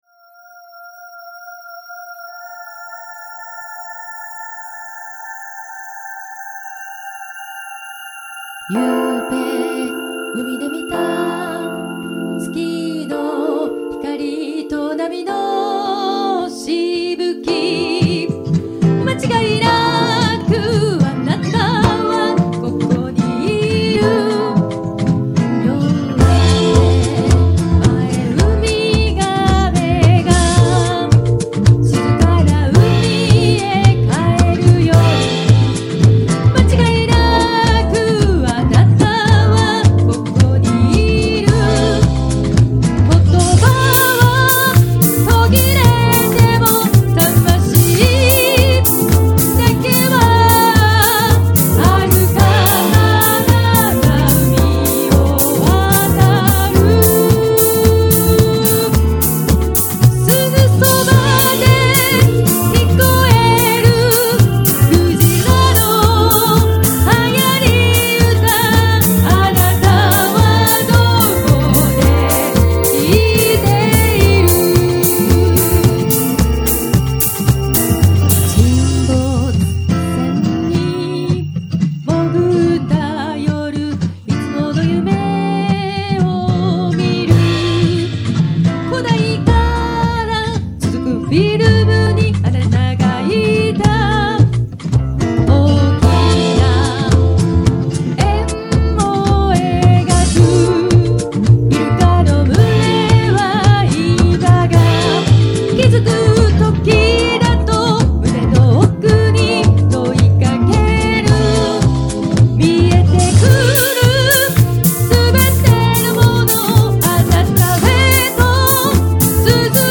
findyourself48keyFmaj.mp3